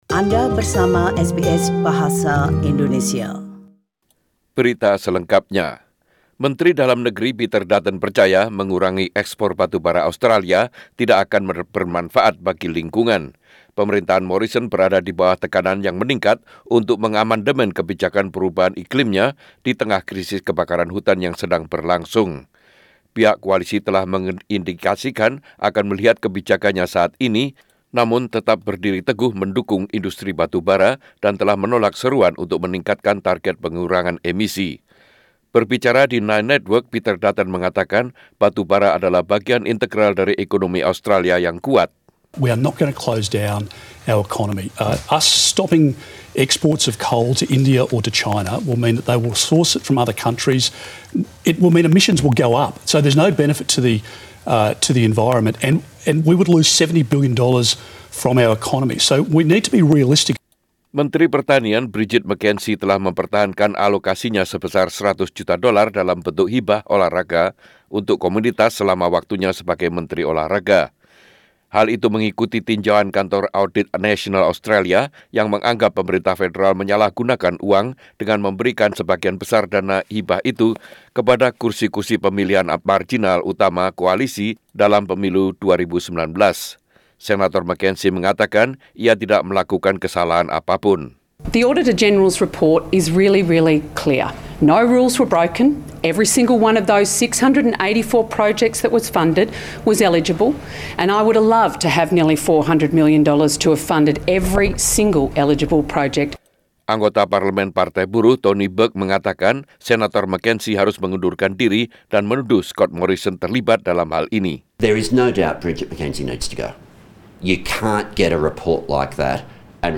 Warta Berita Radio SBS dalam Bahasa Indonesia - 17 Januari 2020